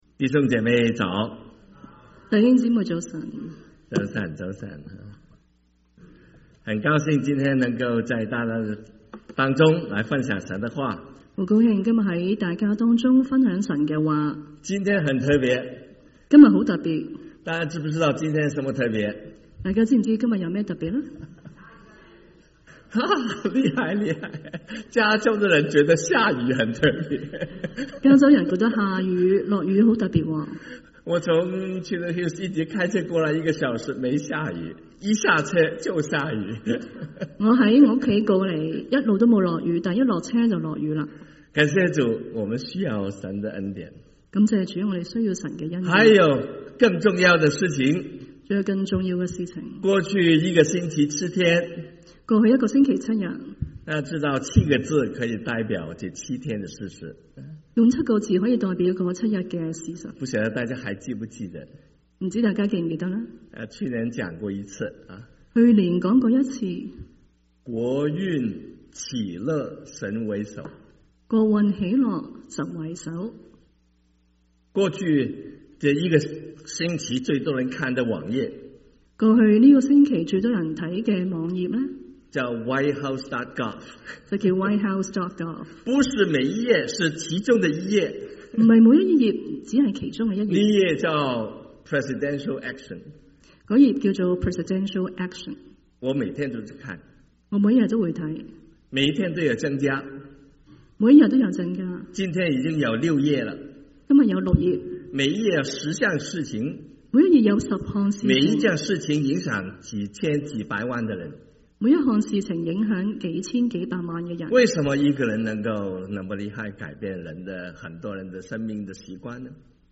1/26/2025 國粵語聯合崇拜: 「主耶穌基督的人—見證, 跟從, 施予」